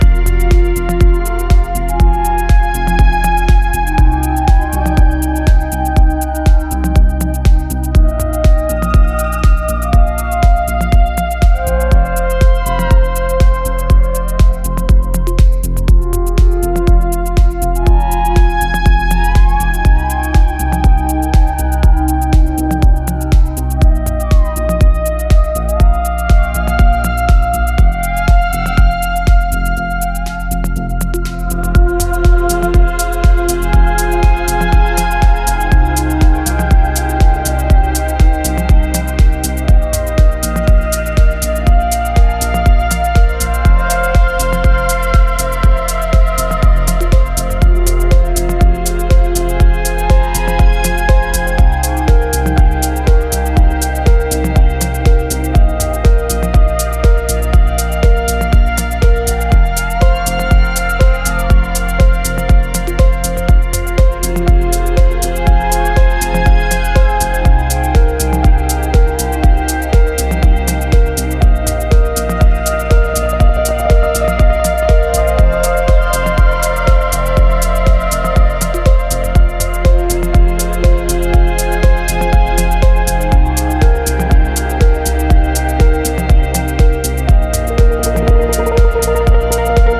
ジャンル(スタイル) PROGRESSIVE HOUSE / DEEP HOUSE